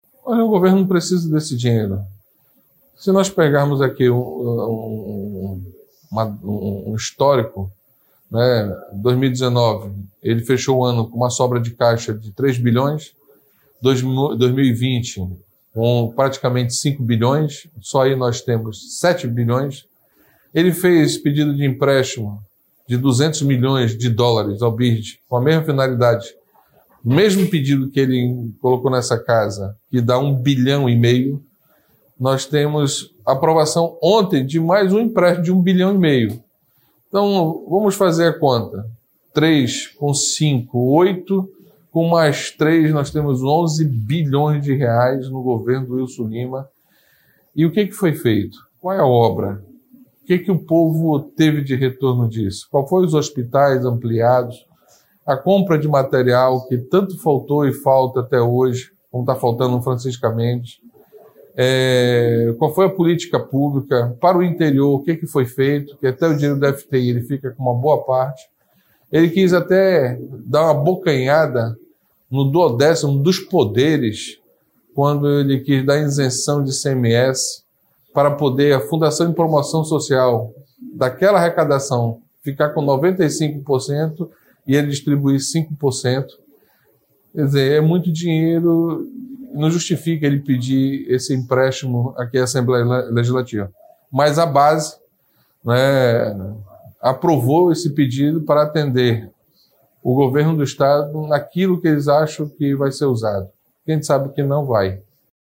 “Meu voto é contra”, afirmou o parlamentar na sessão de quarta-feira (14/7), que aprovou o pedido de empréstimo.